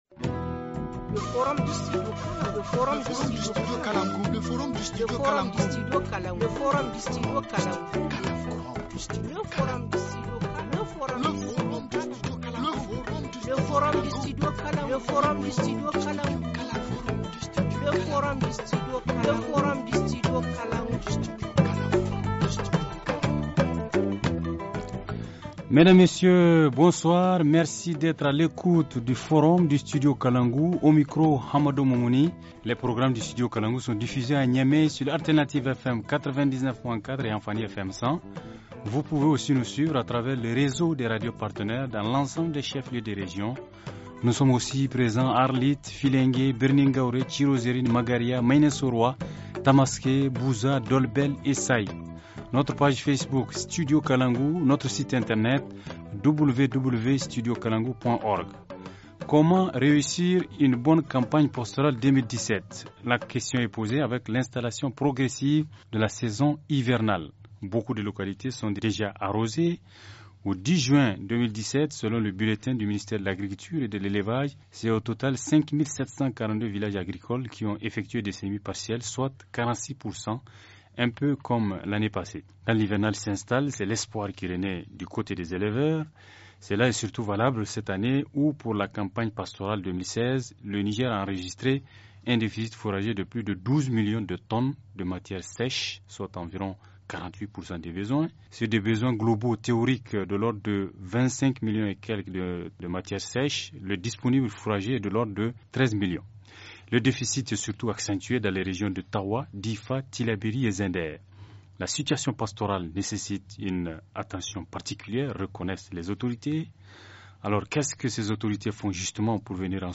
Forum du 3/7/2017 - La campagne pastorale 2017 - Studio Kalangou - Au rythme du Niger